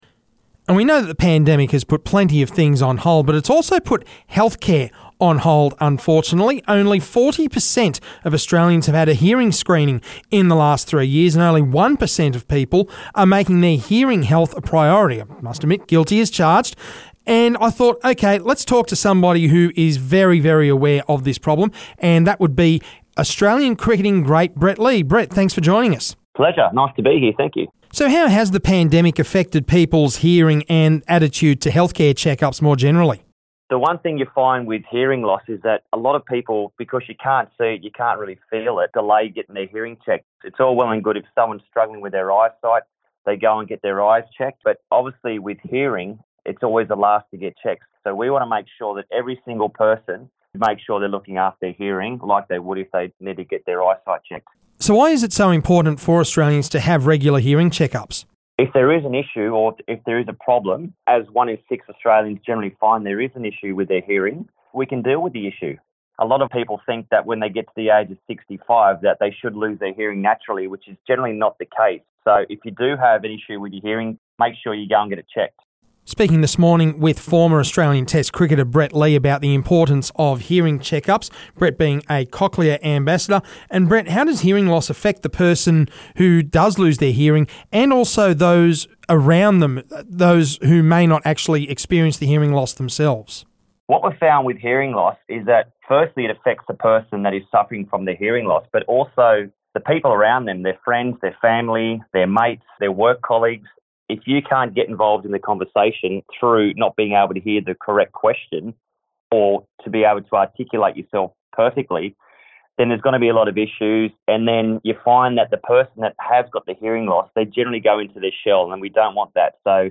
chatted with Aussie cricket legend Brett Lee about the importance of hearing checkups and why so many people are putting it off.